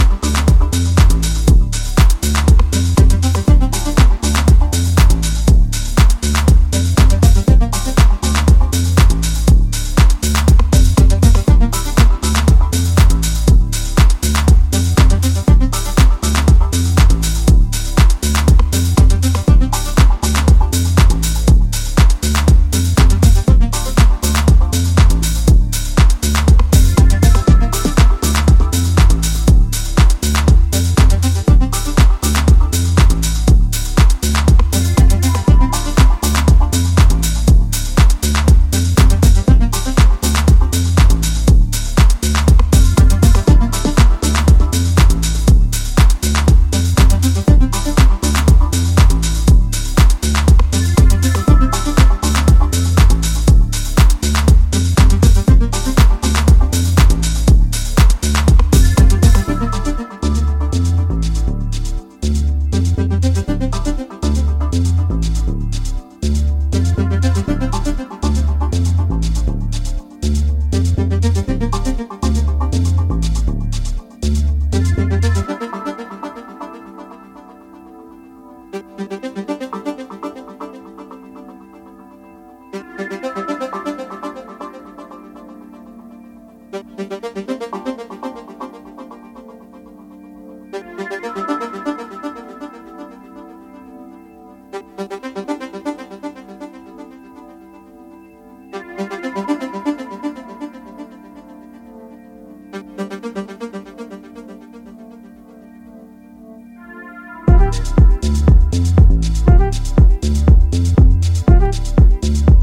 deep, rolling house
scuffed 909's, shrouded pad-work and classic acid lines
tinkering lines of melodic percussion
classic deep house